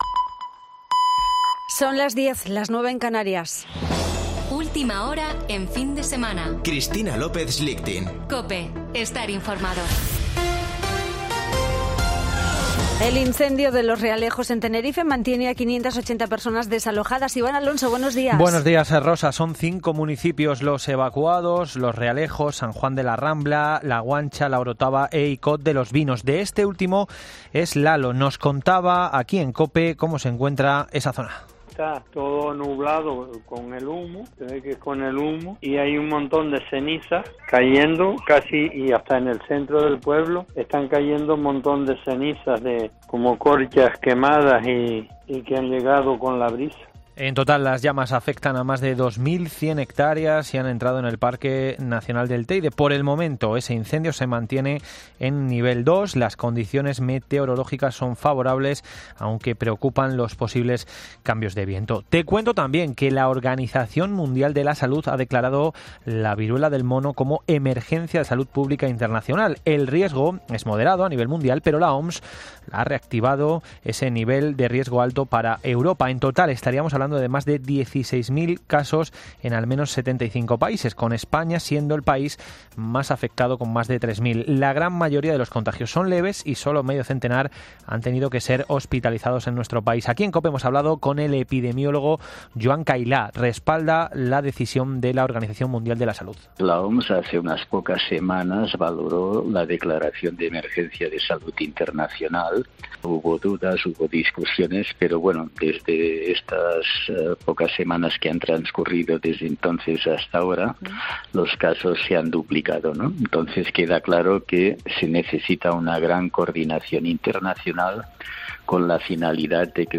Boletín de noticias de COPE del 24 de julio de 2022 a las 10:00 horas